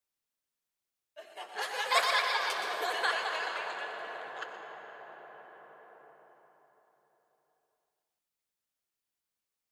На этой странице собраны звуки безумия: маниакальный смех, невнятные крики, стоны и другие проявления психоза.
Загадочный смех детей